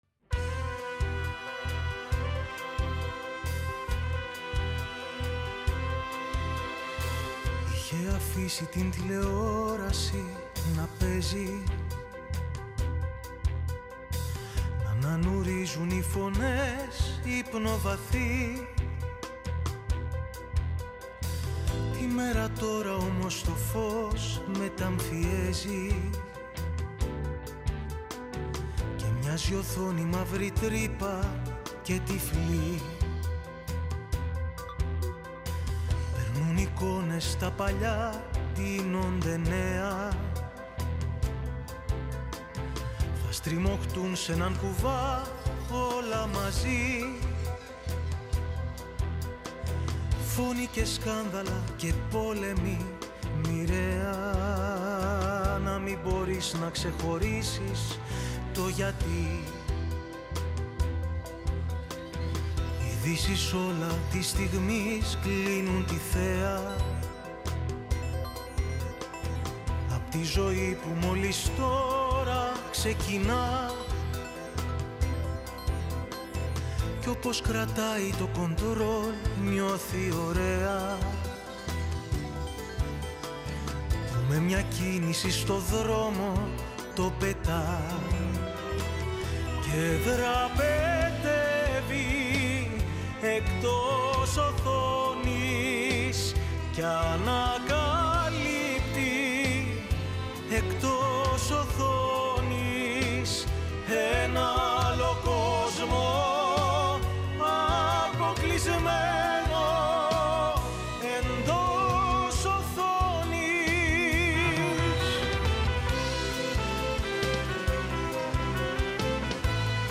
Η συνέντευξη πραγματοποιήθηκε την Τρίτη 17 Μαρτίου 2026 εκπομπή “καλημέρα” στον 9,58fm της ΕΡΤ3